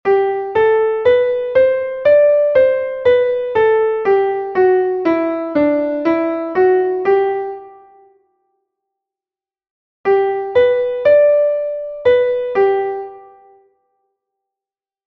Entoación a capella
Escala e arpexio:
escala_arpegio_sol_maior.mp3